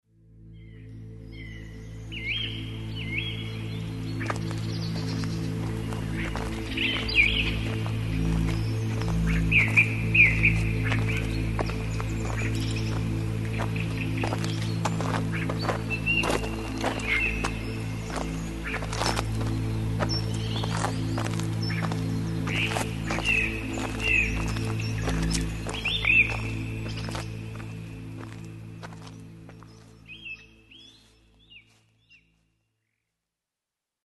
Melomind vous plonge dans un paysage sonore relaxant … le chant des oiseaux de la forêt, le flux des vagues sur le rivage.
son_foret_experience_melomind.mp3